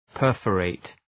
Προφορά
{‘pɜ:rfə,reıt} (Επίθετο) ● διάτρητος (Ρήμα) ● διατρυπώ